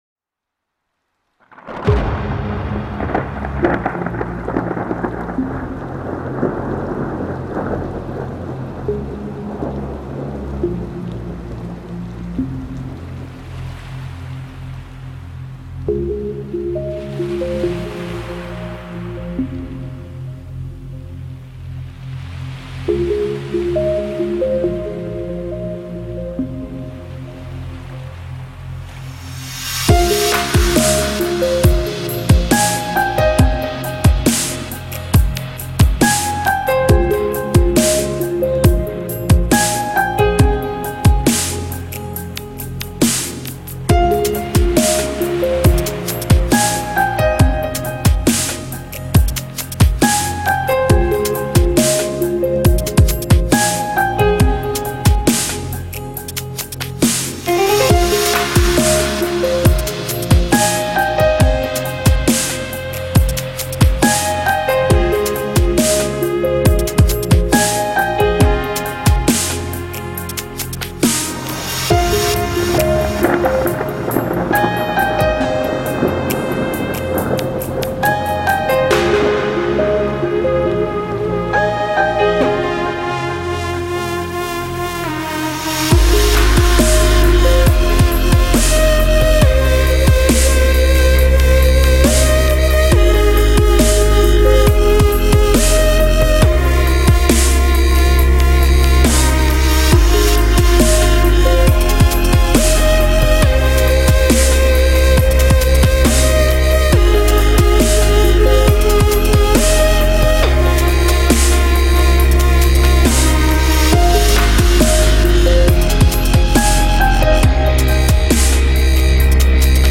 Категория: Dub step